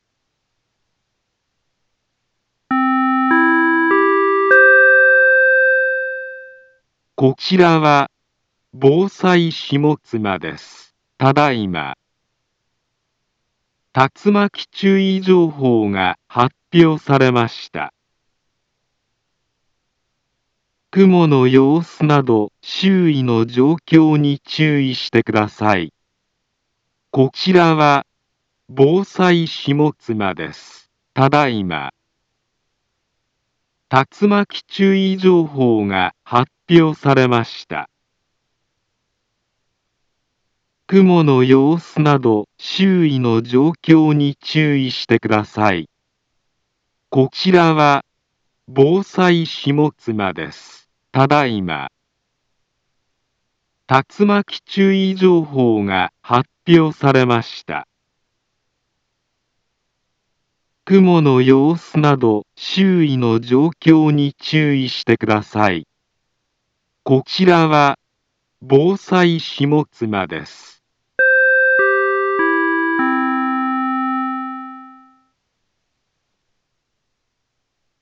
Back Home Ｊアラート情報 音声放送 再生 災害情報 カテゴリ：J-ALERT 登録日時：2023-07-13 20:25:09 インフォメーション：茨城県南部は、竜巻などの激しい突風が発生しやすい気象状況になっています。